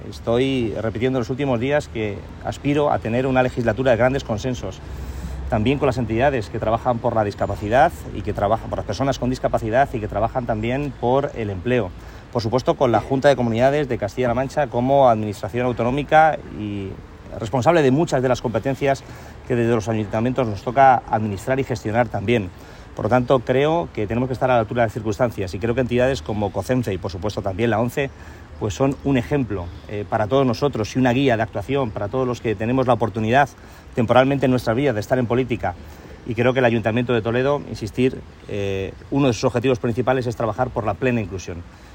Cortes de voz
corte-de-voz-velazquez-participa-en-la-jornada-organizada-por-cocemfe-2.m4a